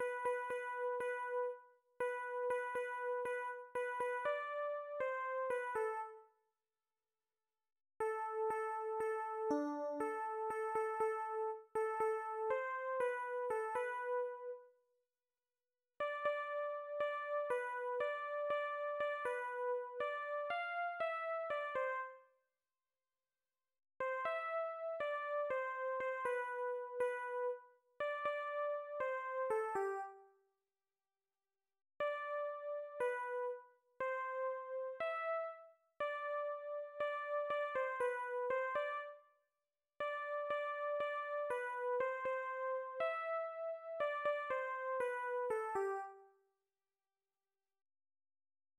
Műfaj magyar könnyűzenei dal